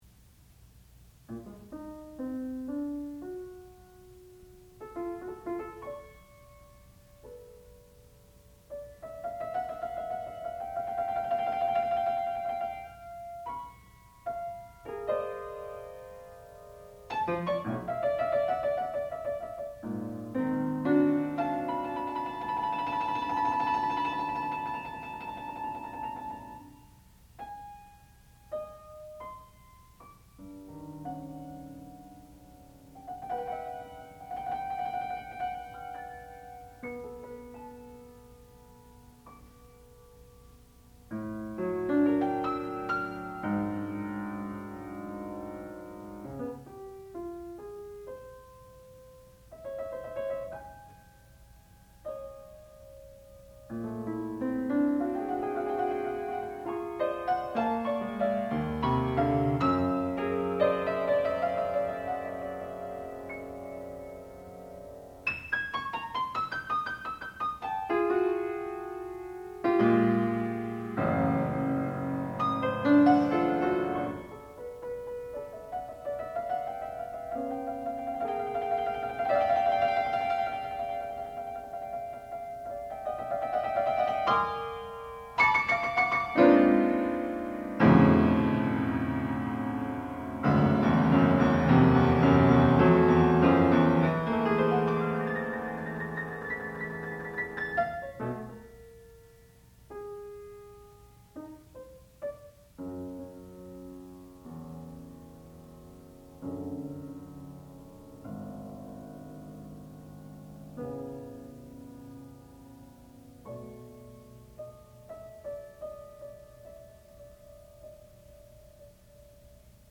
sound recording-musical
classical music
Junior Recital